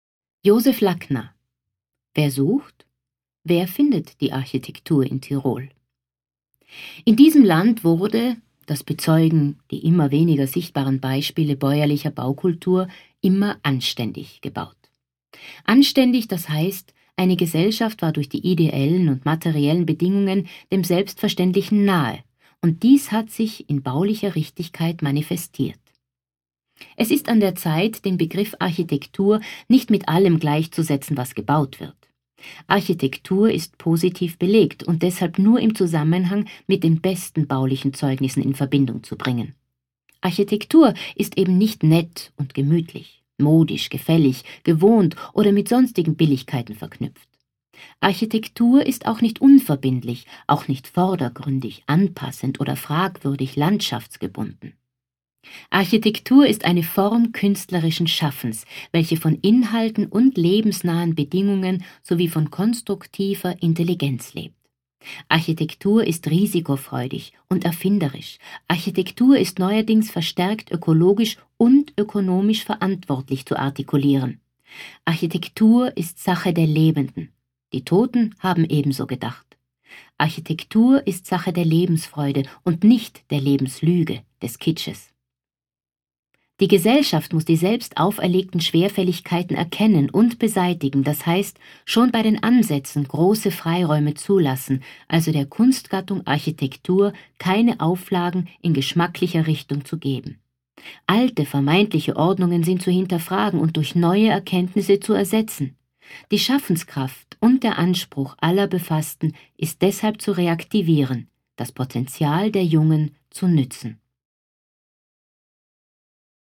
Auszüge aus der Publikation "reprint. ein lesebuch zu architektur und tirol"